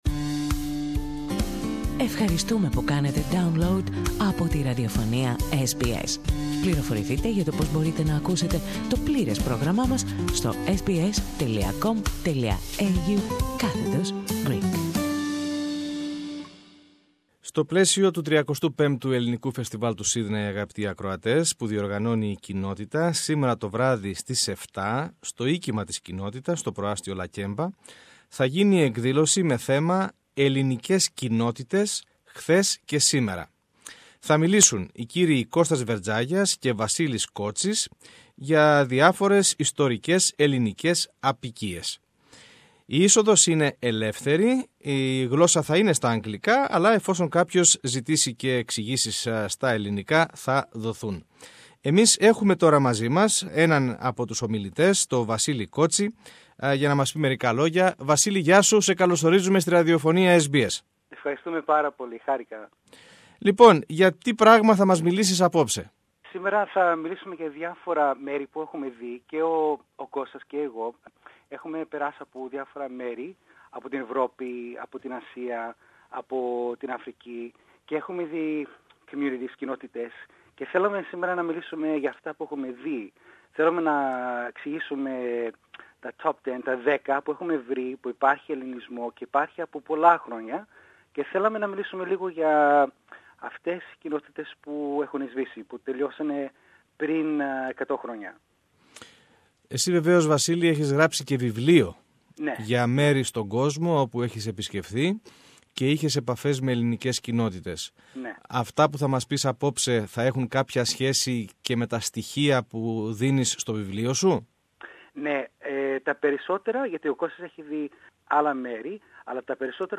συζήτησε με έναν εκ των ομιλητών